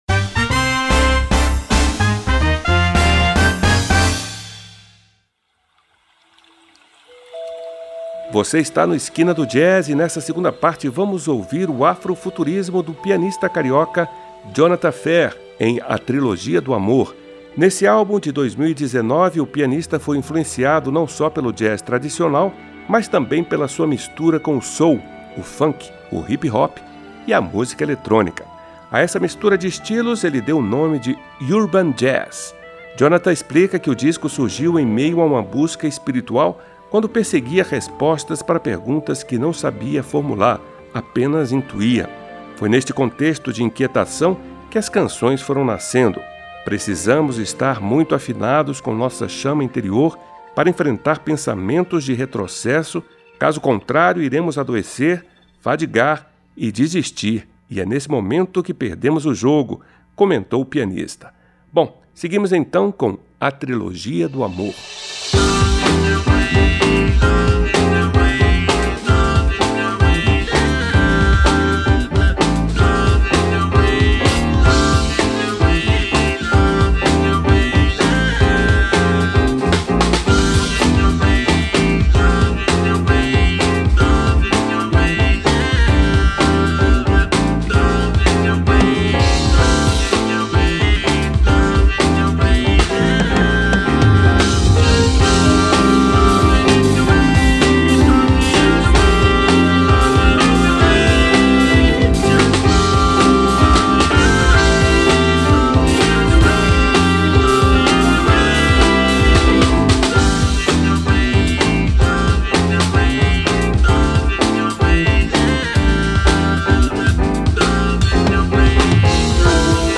o pianista e compositor de Madureira, Rio de Janeiro